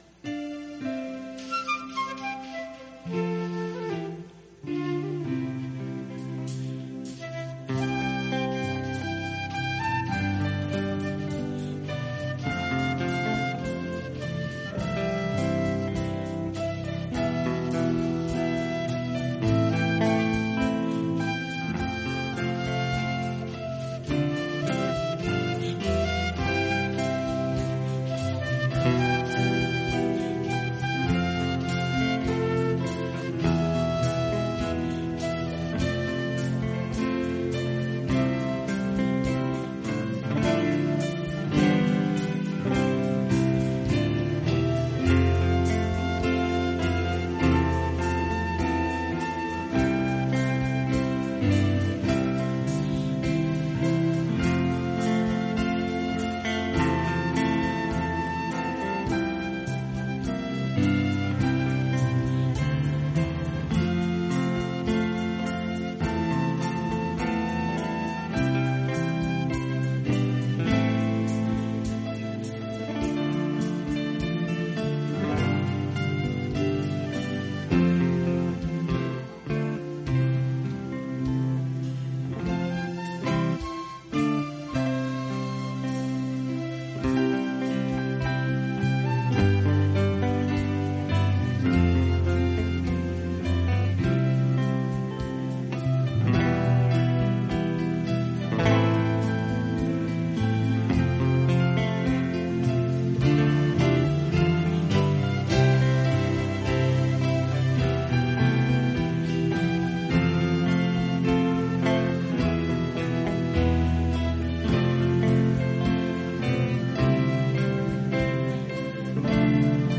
Teachings | Teachings